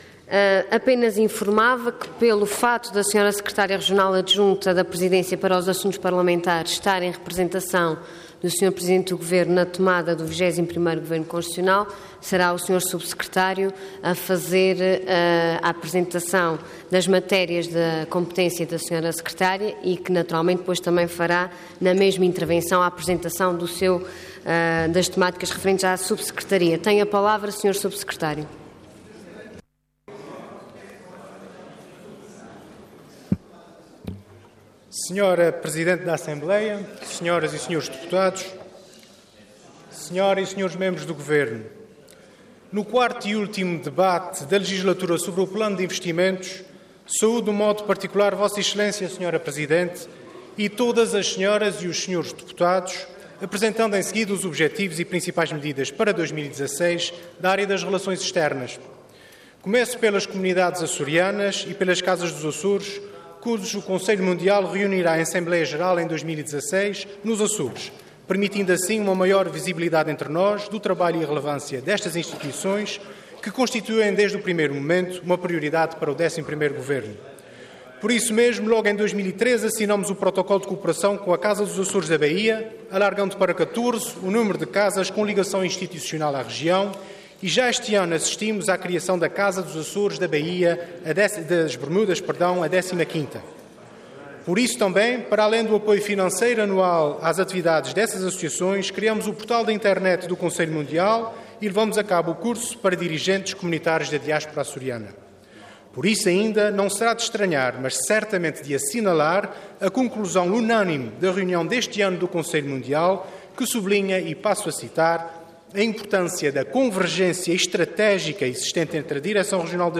Detalhe de vídeo 26 de novembro de 2015 Download áudio Download vídeo X Legislatura Plano e Orçamento para 2016 - Relações Externas e Assuntos Parlamentares Intervenção Proposta de Decreto Leg. Orador Rodrigo Oliveira Cargo Subsecretário Regional da Presidência Para as Relações Externas Entidade Governo